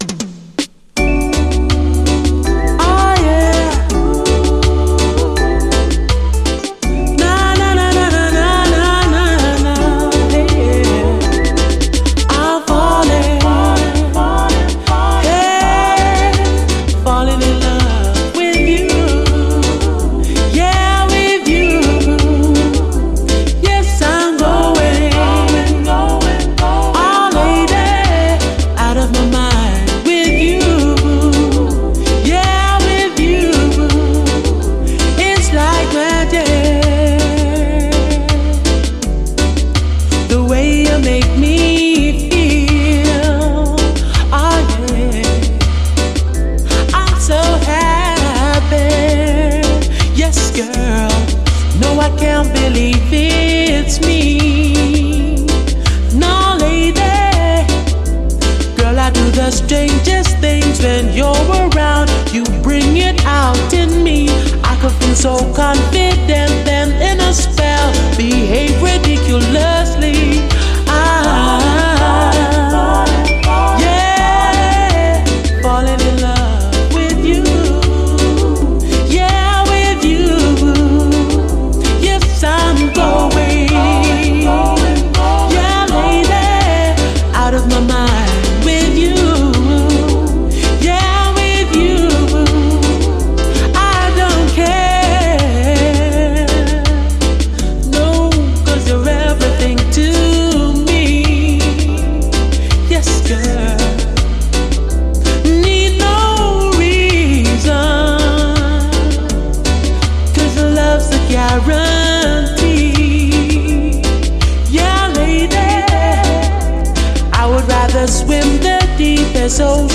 REGGAE
リゾート感あるスムース・メロウ・ラヴァーズ好曲！